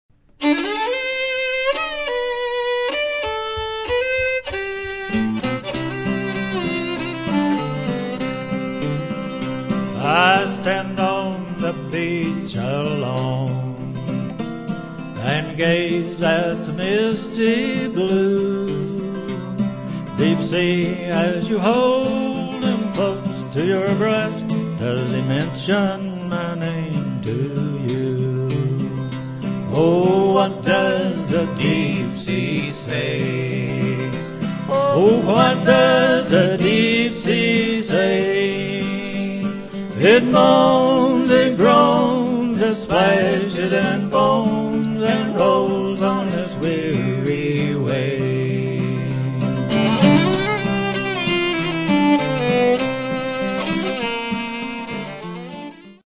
Old-Time Songs & Longbow Fiddle
fiddle and lead vocals
guitar and harmony vocals.